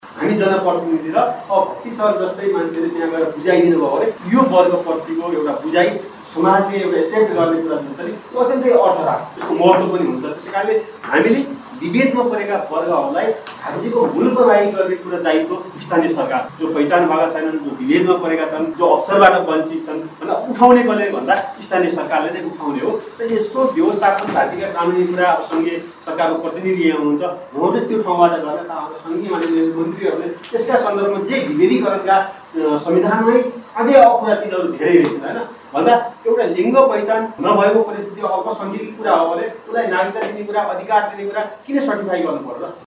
निलहिरा समाजले सुर्खेतको वीरेन्द्रनगरमा आयोजना गरेको अन्तरक्रिया कार्यक्रममा सहभागि भएका उनिहरुले यस्तो बताएका हुन् ।
वीरेन्द्रनगर ८ नम्बर वडा अध्यक्ष टिकाराम शर्माले पनि सबै समुदायलाई समान सहभागिताको परिकल्पना संविधानले गरेको भन्दै यसको कार्यान्वयनमा जोड दिनुभयो ।